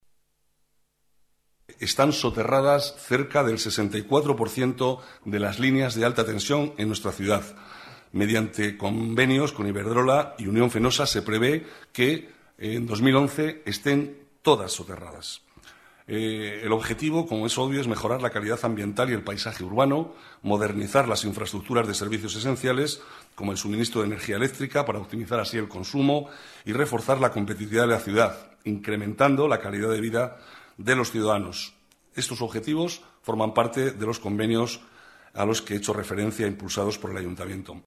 Nueva ventana:Declaraciones del vicealcalde, Manuel Cobo: Soterramiento líneas de alta tensión